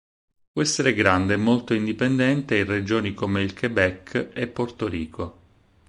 in‧di‧pen‧dèn‧te
/in.di.penˈdɛn.te/